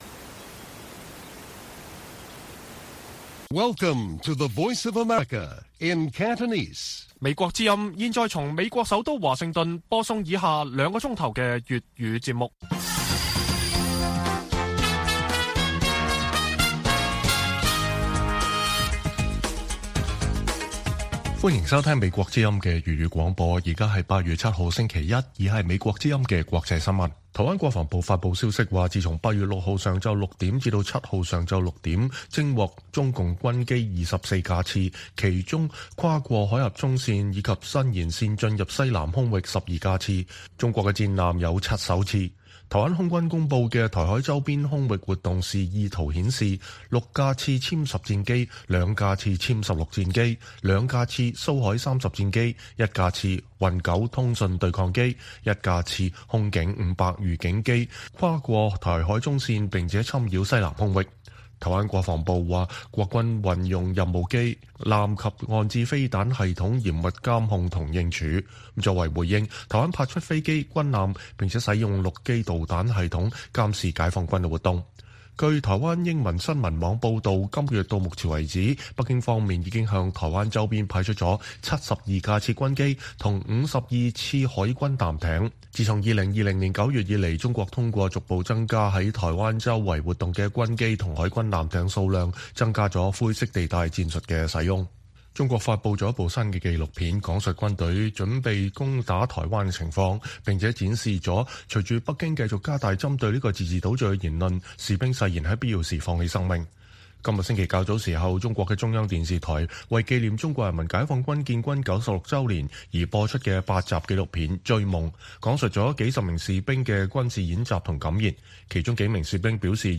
粵語新聞 晚上9-10點: 台灣稱中國機艦再次逾越台海中線侵擾西南空域